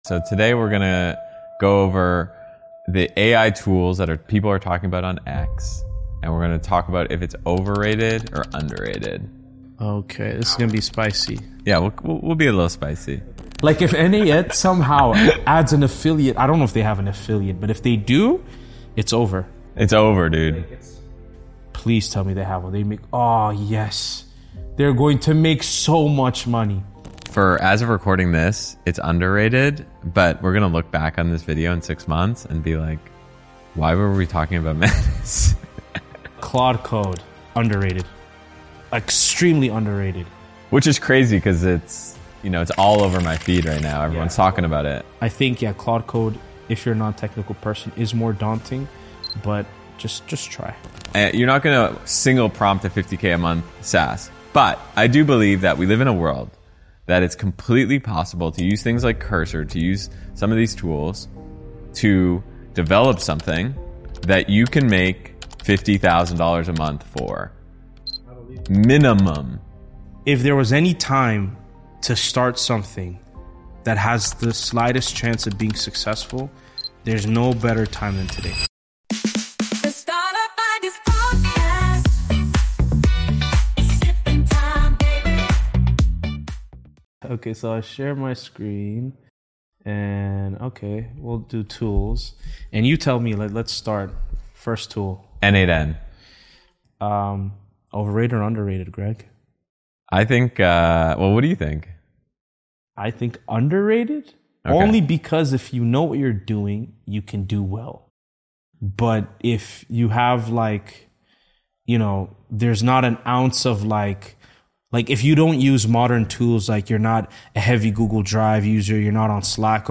We particularly highlight Claude Code as "extremely underrated" and discuss how tools like Devin and CodeRabbit are changing development workflows. The conversation also addresses my controversial viral tweet about building profitable SaaS businesses with AI tools.